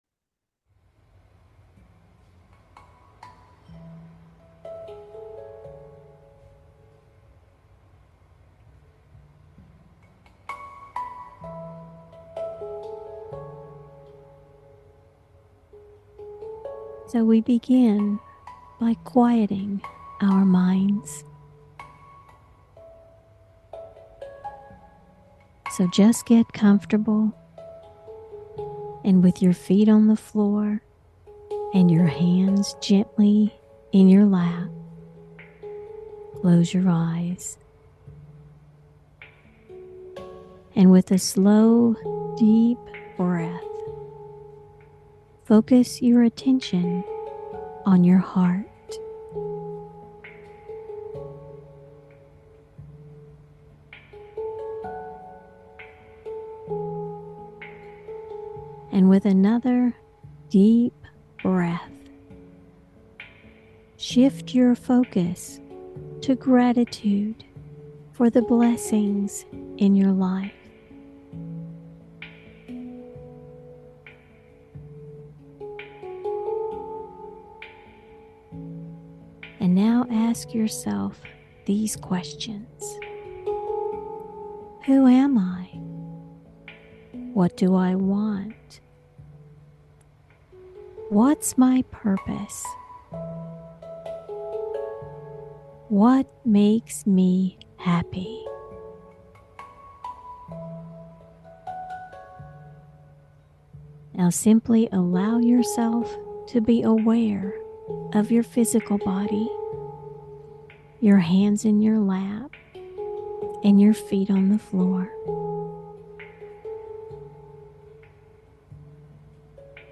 Angel-Love-Connection-Meditation.mp3